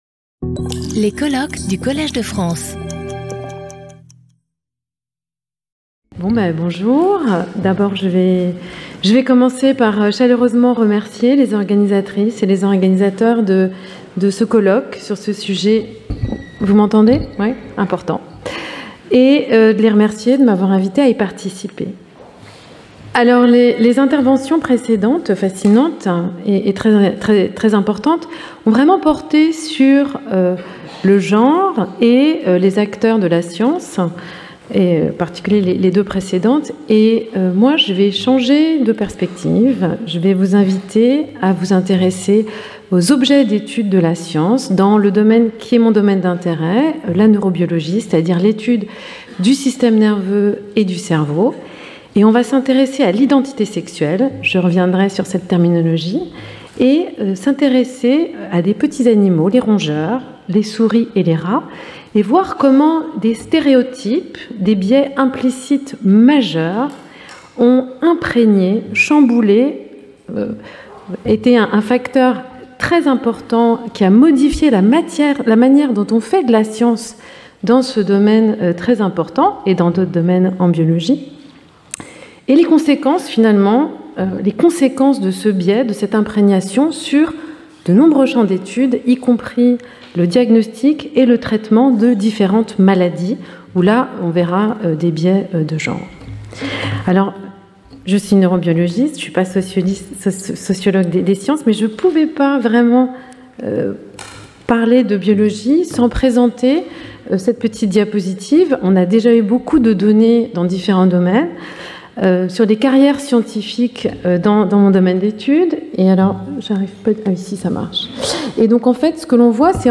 Chaque communication de 30 minutes est suivie de 10 minutes de discussion.